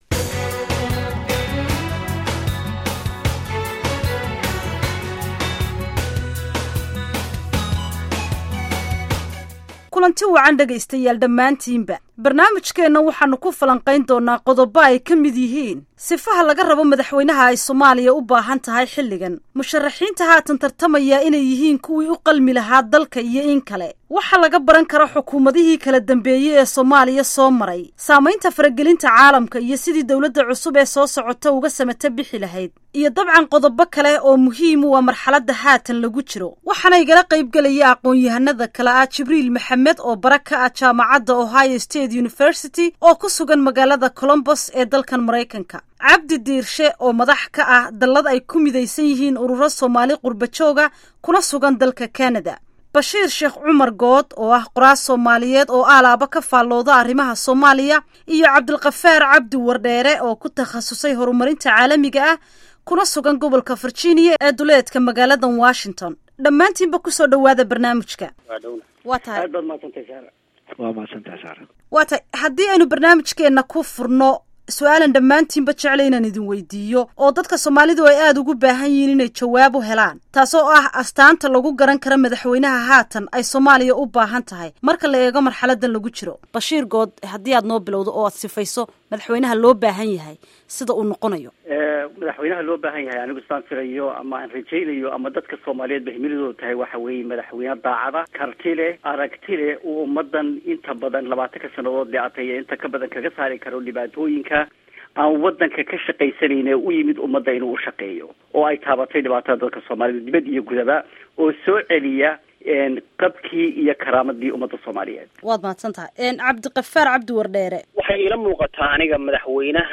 Dhageyso Doodda Aqoonyahannada